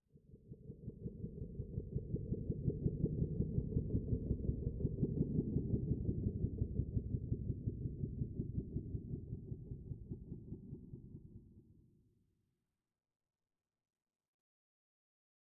Minecraft Version Minecraft Version latest Latest Release | Latest Snapshot latest / assets / minecraft / sounds / ambient / nether / nether_wastes / addition4.ogg Compare With Compare With Latest Release | Latest Snapshot